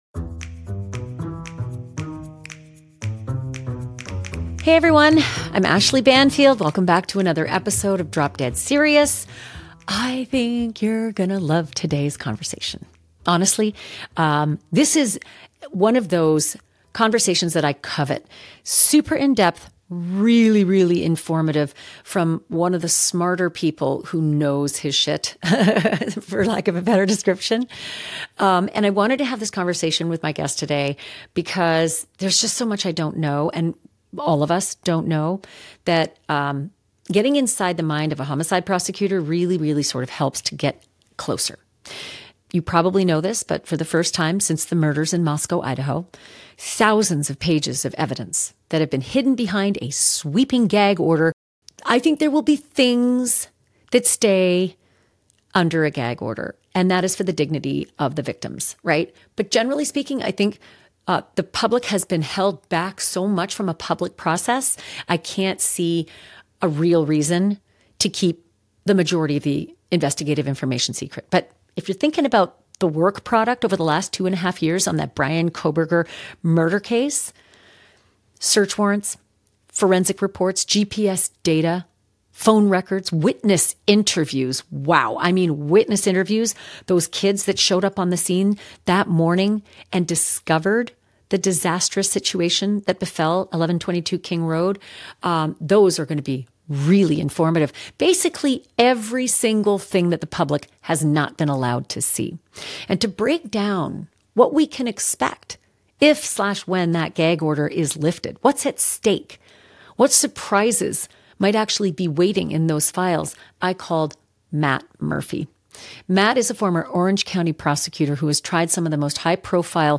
Former homicide prosecutor